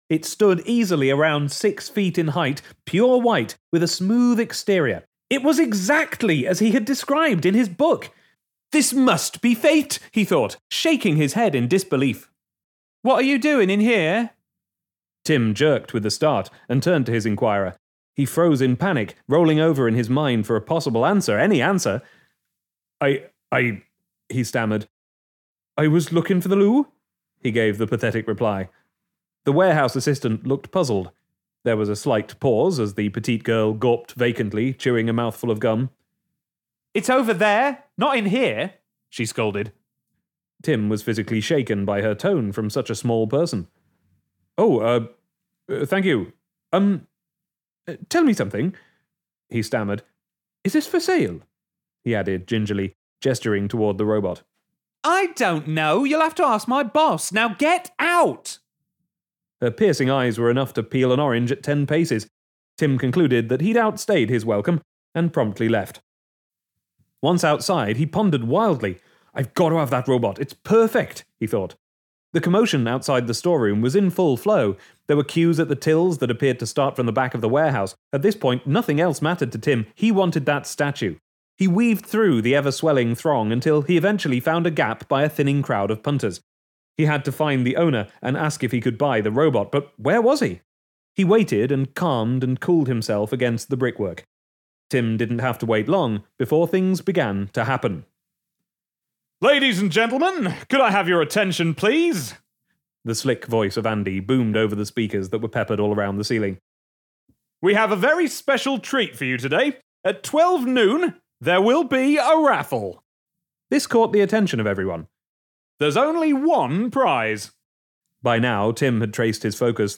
'Crank Tech One: Destruction' Audiobook Available Now